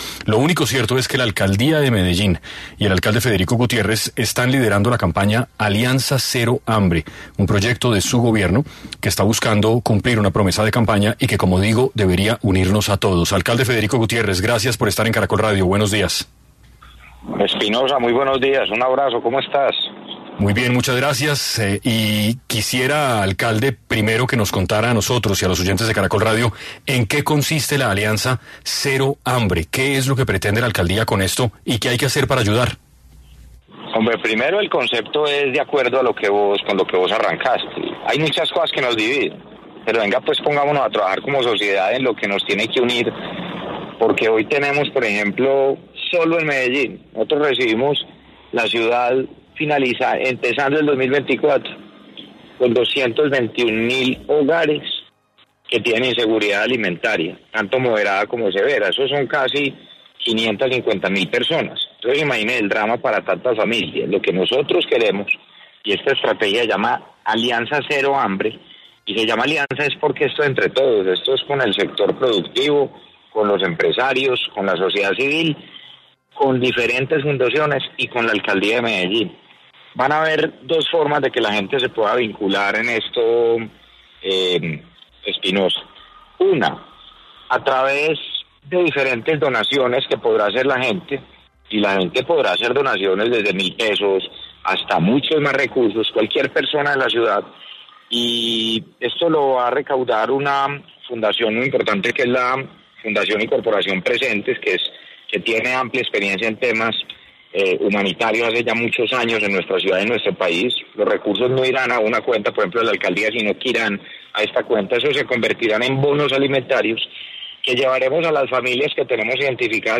En Caracol Radio estuvo Federico Gutiérrez, alcalde de Medellín, explicando la iniciativa